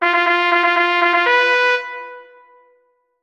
Bugle Call